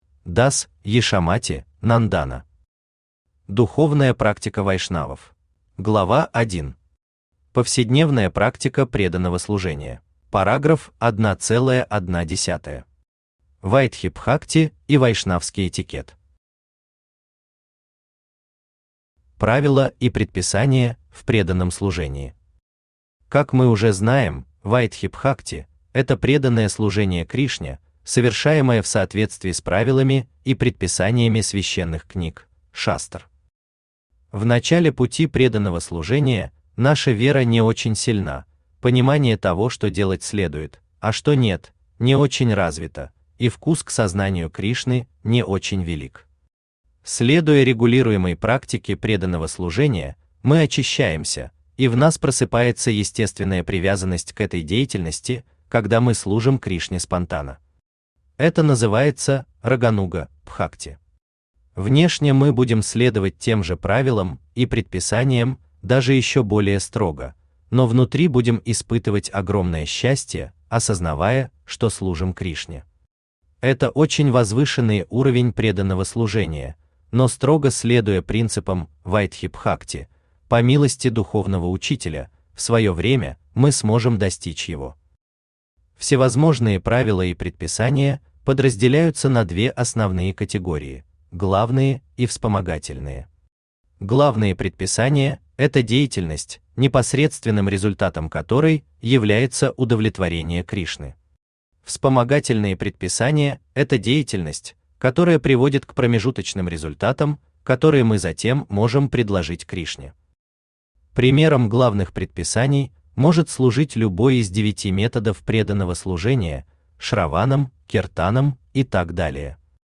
Аудиокнига Духовная Практика Вайшнавов | Библиотека аудиокниг
Aудиокнига Духовная Практика Вайшнавов Автор дас Яшомати Нандана Читает аудиокнигу Авточтец ЛитРес.